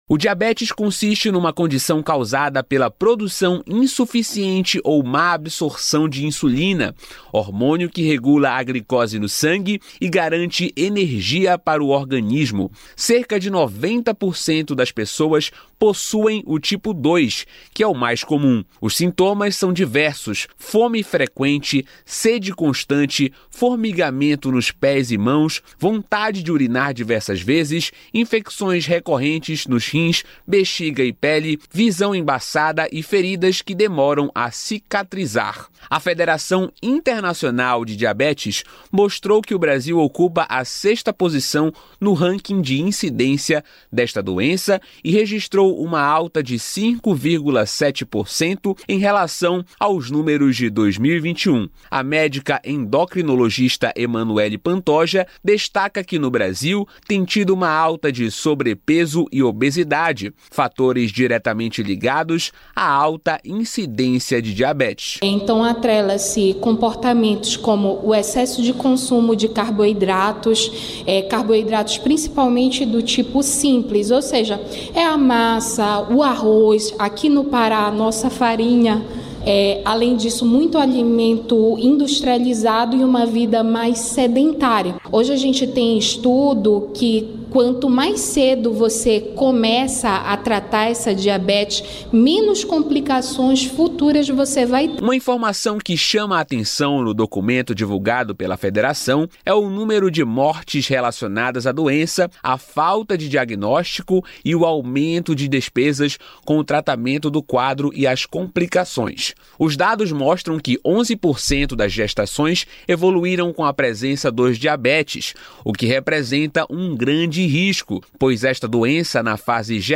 repórter da Rádio Nacional